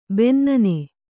♪ bennane